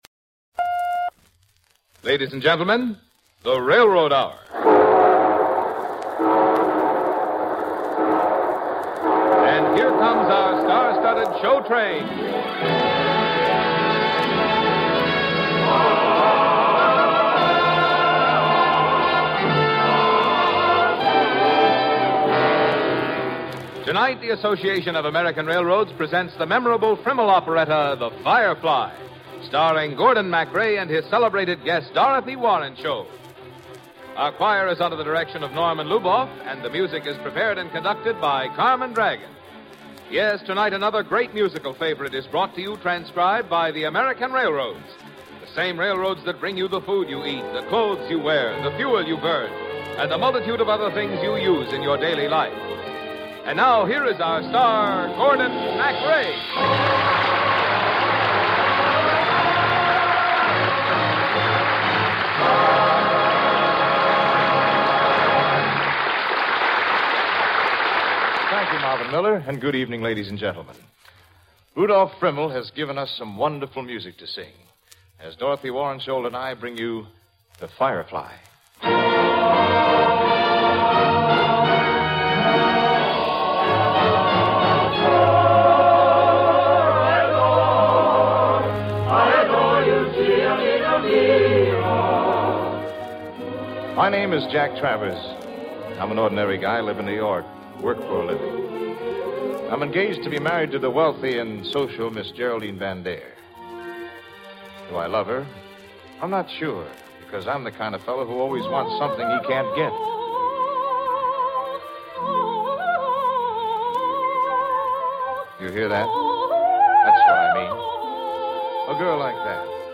radio series that aired musical dramas and comedies
hosted each episode and played the leading male roles